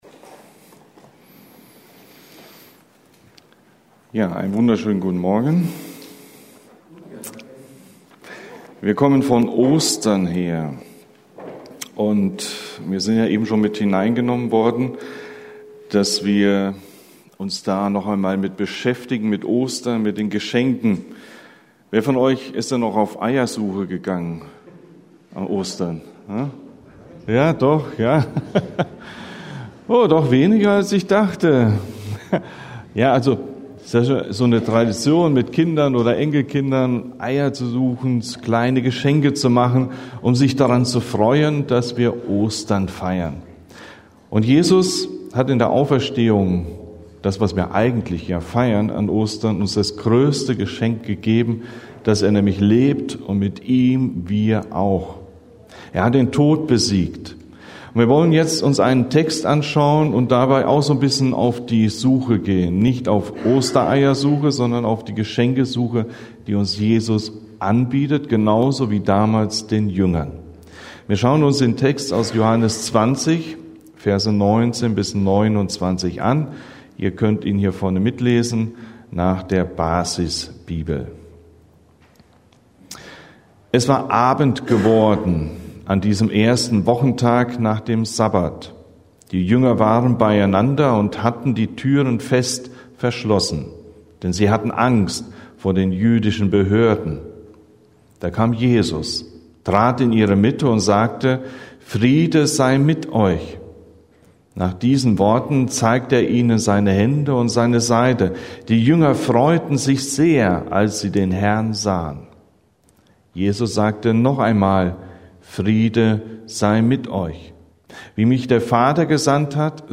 Wendepunkt – Predigten: Gemeinschaftsgemeinde Untermünkheim